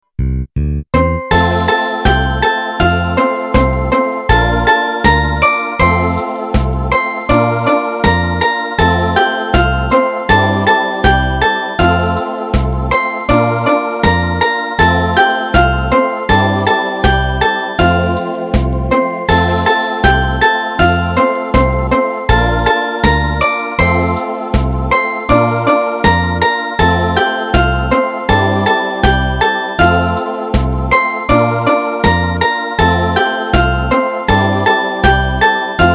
(минусовка)